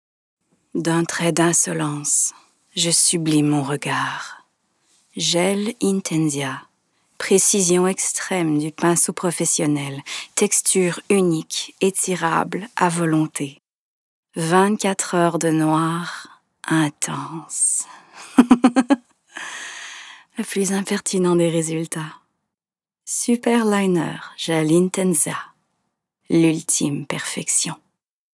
Âge naturelle de la voix Jeune adulte
Timbre Médium - Grave - Petit grain chaleureux
L'Oréal superliner - Diction impeccable - Sensuelle/sexy - Français normatif / Publicité